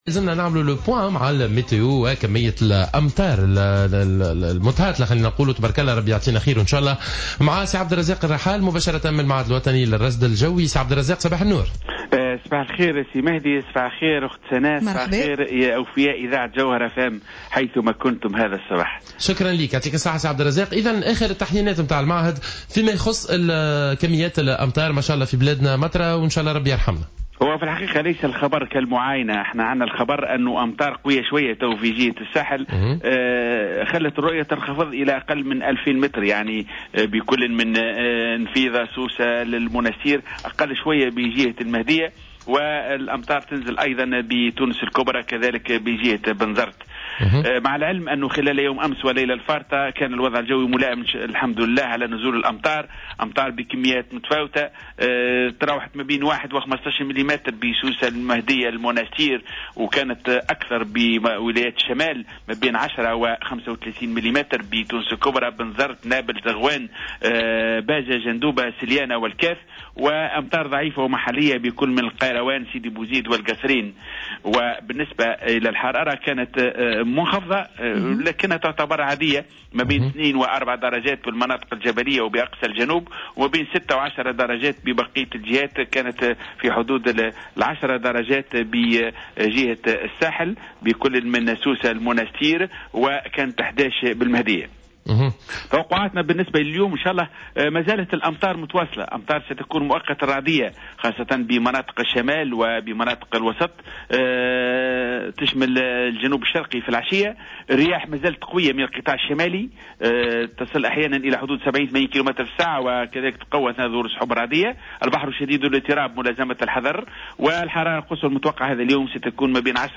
خلال مداخلته صباح اليوم على موجات الجوهرة اف ام